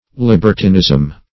libertinism - definition of libertinism - synonyms, pronunciation, spelling from Free Dictionary
Libertinism \Lib"er*tin*ism\ (-t[i^]n*[i^]z'm), n.